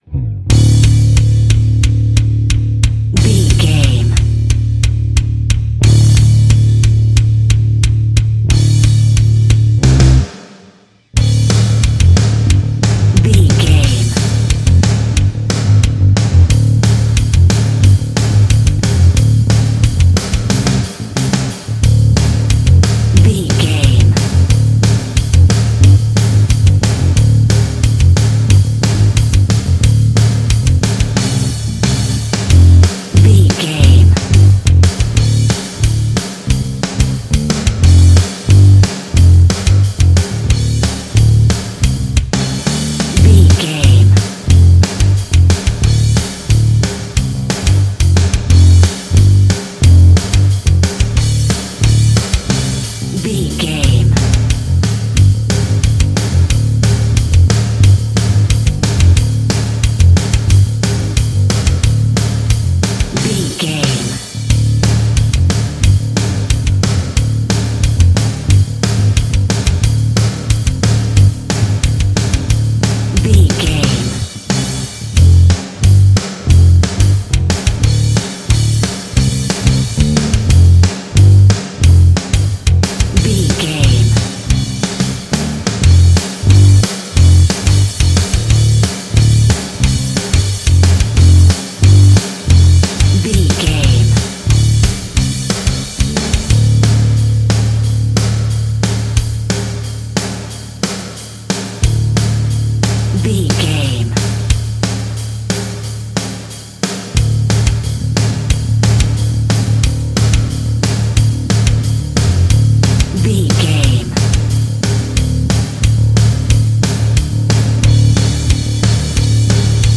Aeolian/Minor
Fast
aggressive
industrial
intense
driving
dark
heavy
bass guitar
electric guitar
drum machine